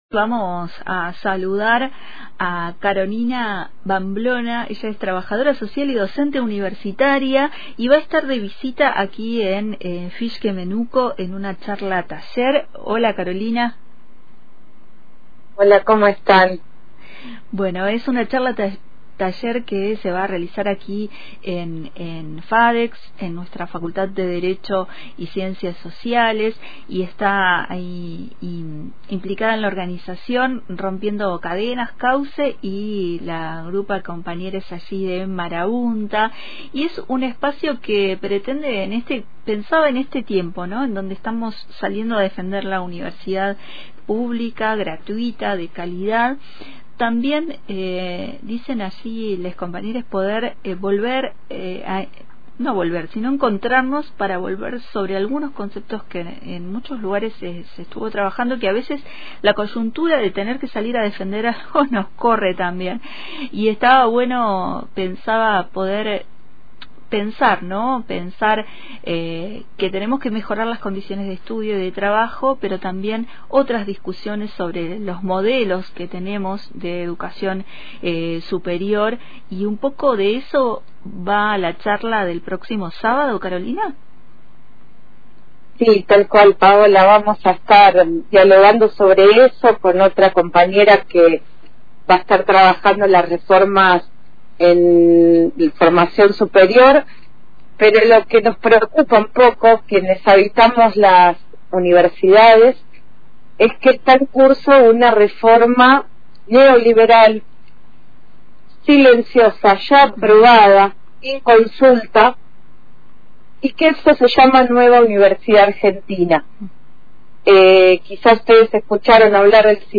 En entrevista con 2El Hilo Invisible»